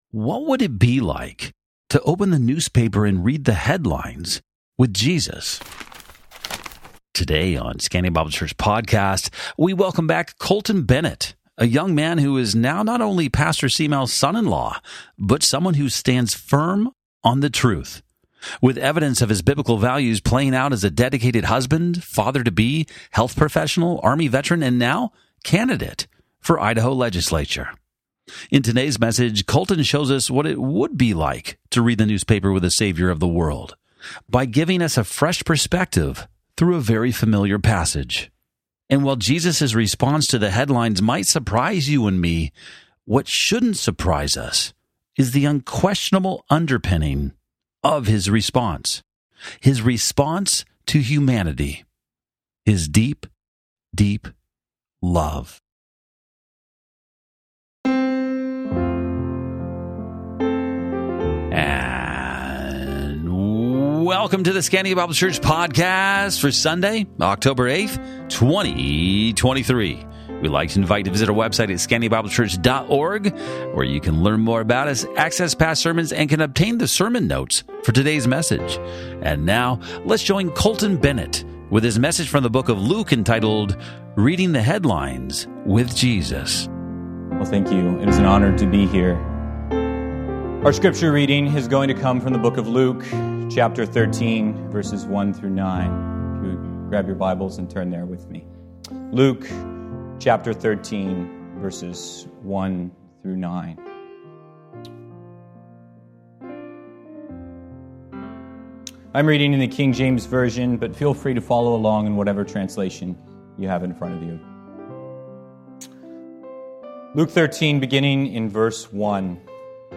Sermon Notes
guest preacher